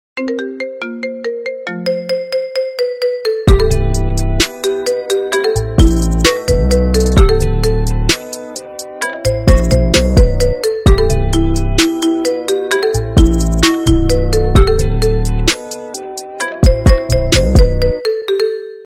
Marimba Remix